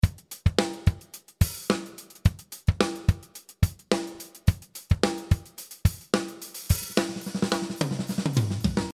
This loop song contains 38 loop variations, In total length of more then 4:00 minutes. 11 loops with tom fills, patterns with cymbals and big snare.
Product tags pop (19) , acoustic drum loops (90) Be the first to review this product